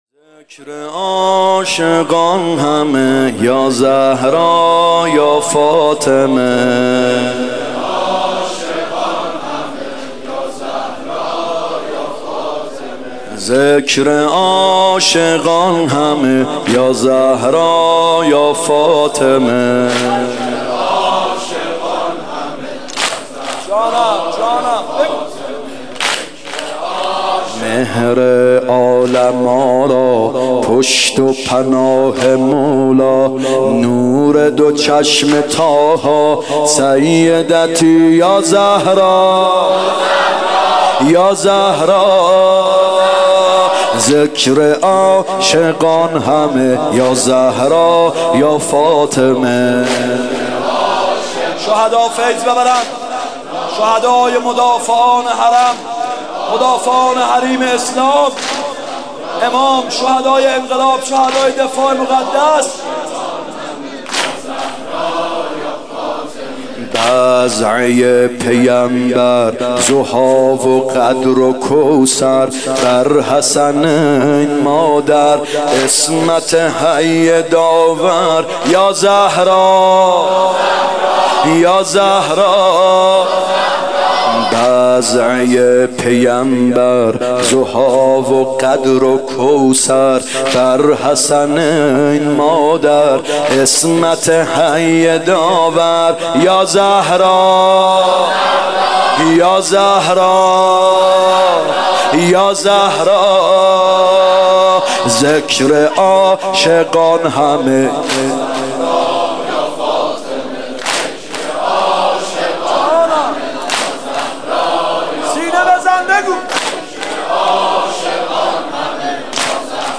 فاطمیه هیات یامهدی عج